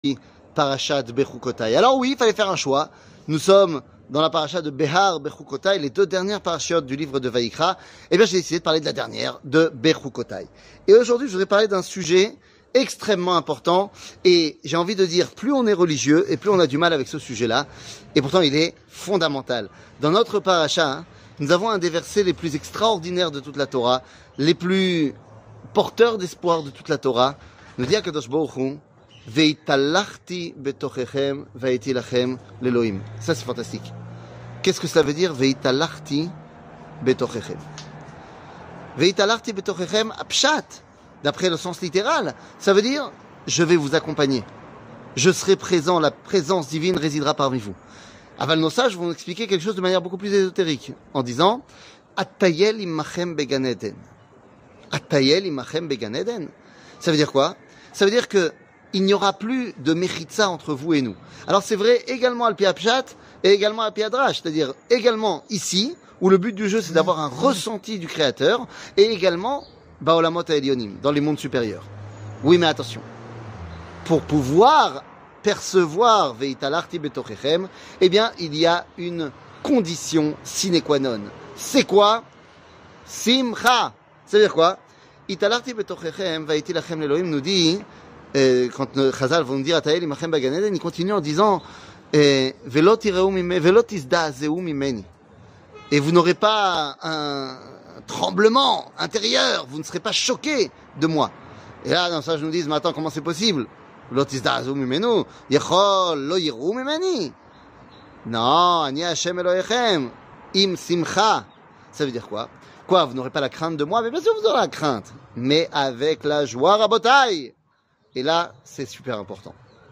Le point Paracha, Behoukotai, Sois heureux 00:04:57 Le point Paracha, Behoukotai, Sois heureux שיעור מ 11 מאי 2023 04MIN הורדה בקובץ אודיו MP3 (4.52 Mo) הורדה בקובץ וידאו MP4 (8.07 Mo) TAGS : שיעורים קצרים